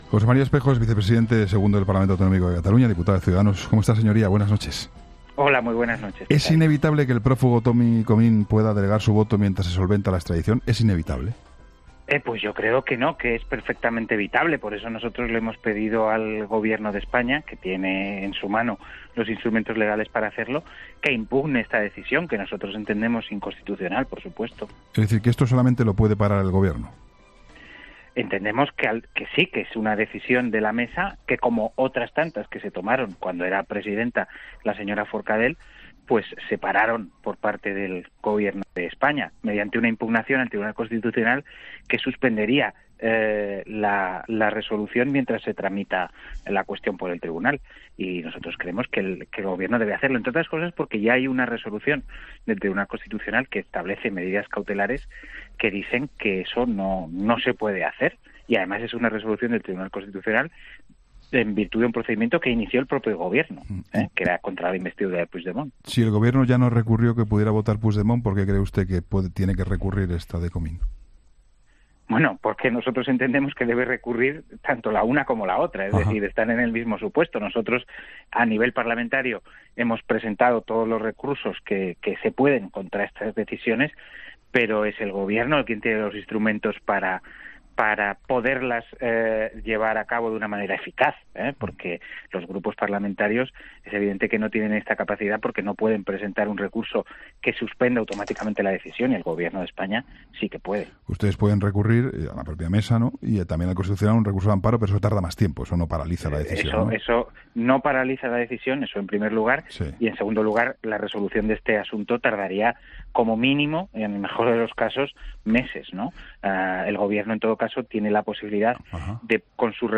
Entrevistas en La Linterna
José María Espejo, vicepresidente segundo del Parlament ha explicado en 'La Linterna' con Juan Pablo Colmenarejo que el recurso del Gobierno al voto delegado de Comín lo paralizaría inmediatamente